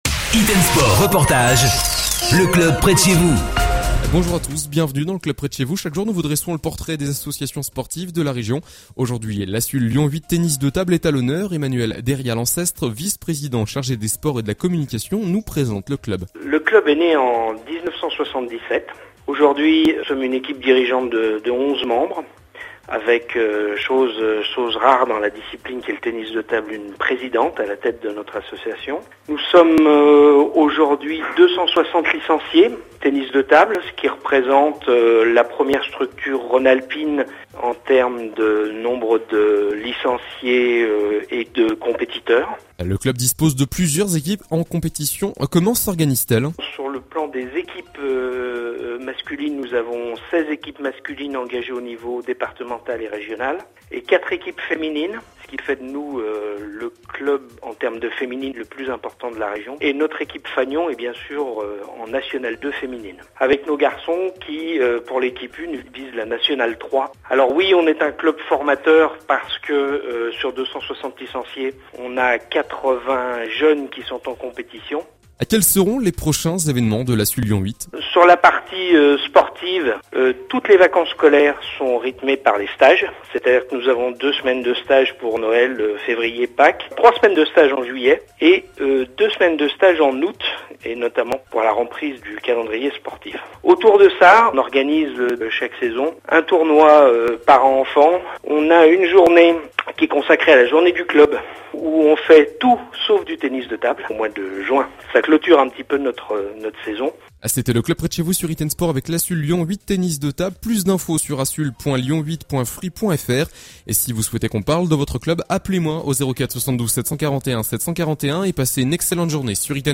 Hits & Sports : Spot Radio